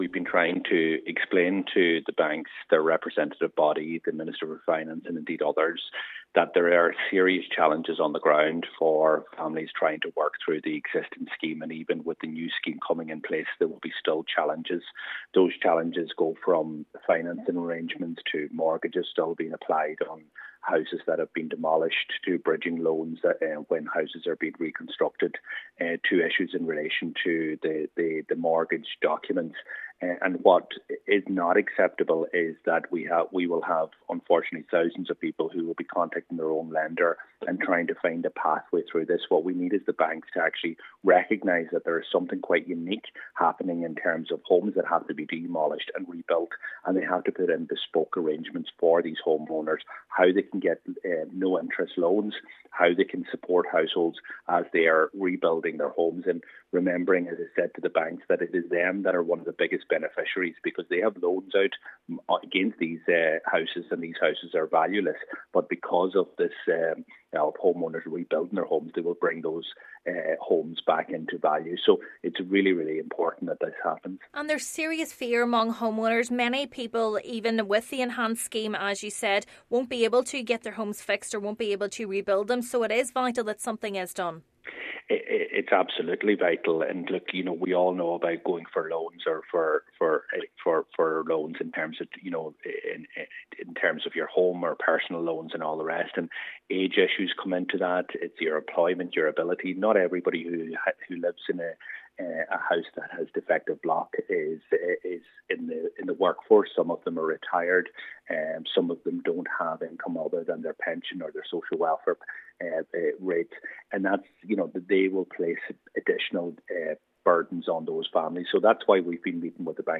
Deputy Doherty says the unique situation facing those affected by the defective block issue needs to be considered: